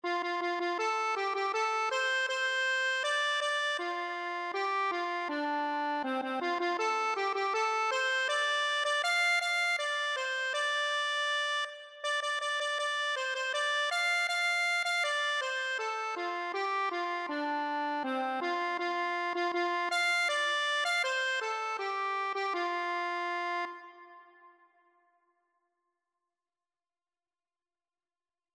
Free Sheet music for Accordion
Traditional Music of unknown author.
2/2 (View more 2/2 Music)
F major (Sounding Pitch) (View more F major Music for Accordion )
Traditional (View more Traditional Accordion Music)